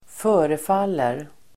Uttal: [²f'ö:refal:er]